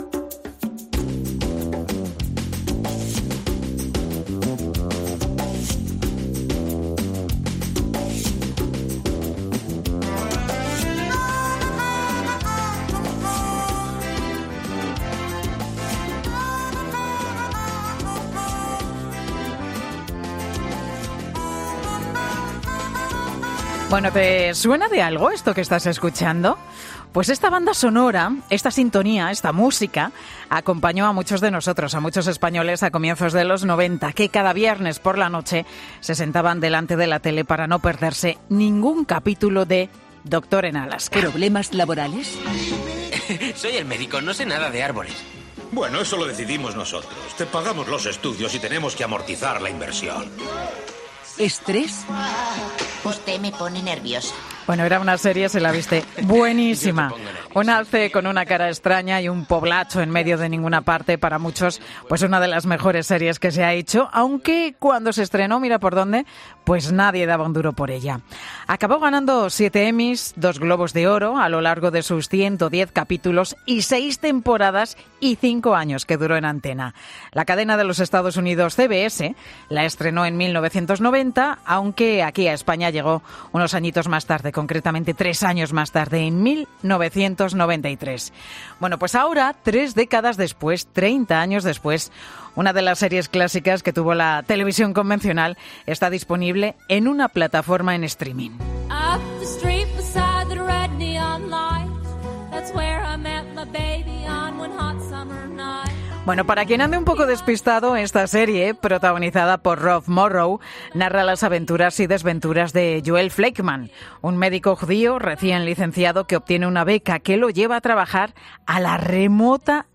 En Mediodía COPE, dos 'Alaskers' cuentan sus encuentros anuales para recordar la serie